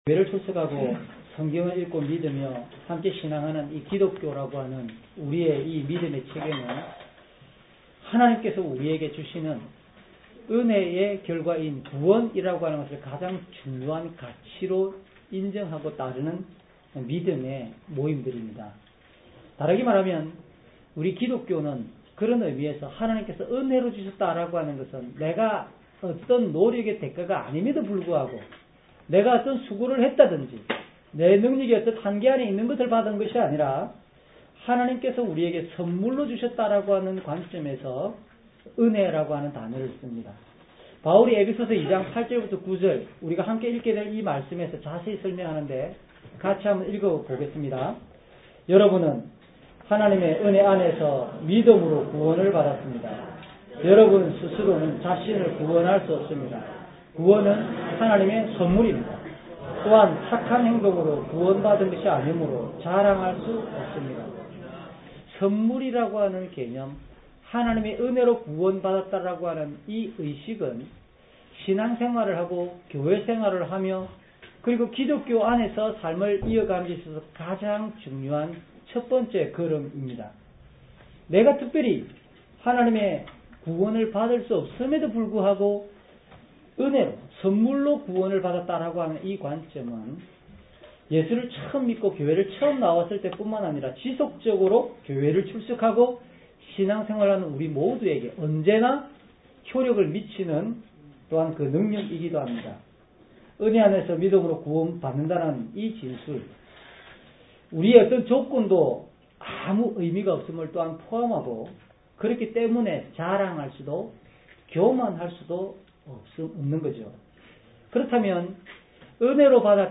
주일설교 - 08년 12월 21일 "은혜에 대한 감각을 더욱 키웁시다." (눅7:36-50)
08년 12월 21일 주일 설교 "은혜에 대한 감각을 더욱 키웁시다." (눅7:36-50)